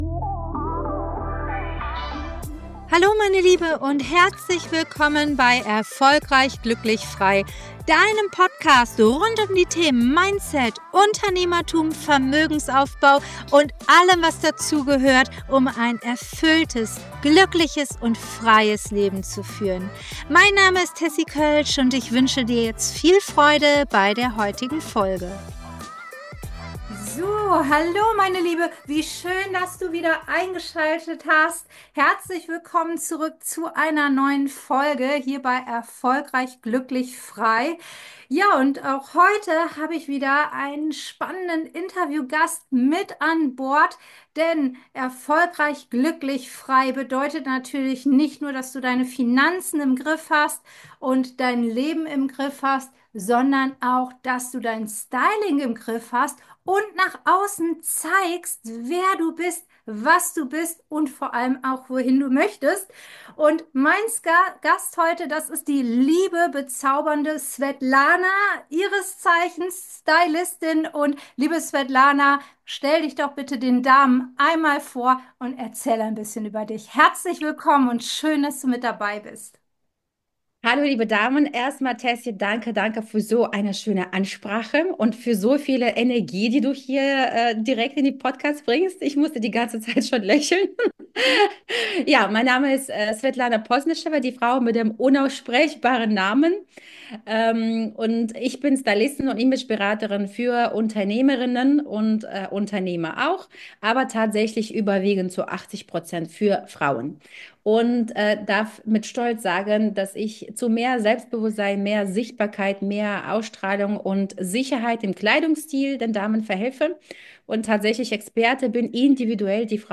#8 Was soll ich bloß anziehen? Styling, Selbstbild und Erfolg – Interview